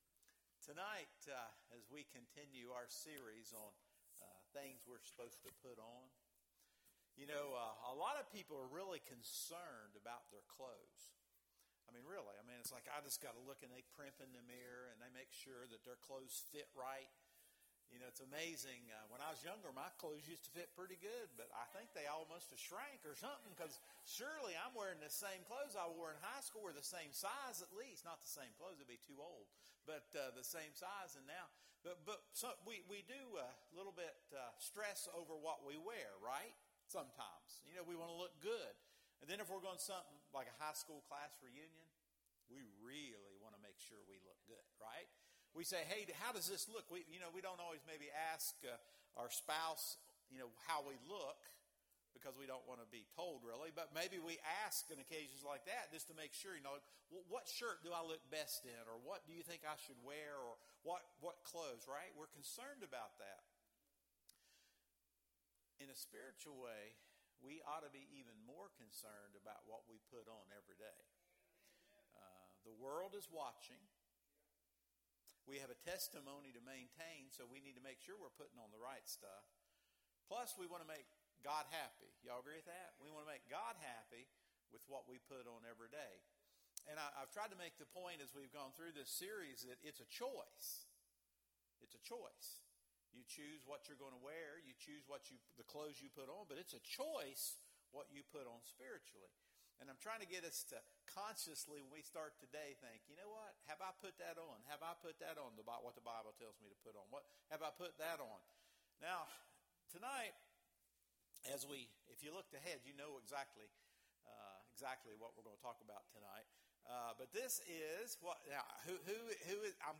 Sermons | Oak Mound Evangelical Church